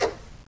Tumba-Tap1_v1_rr1_Sum.wav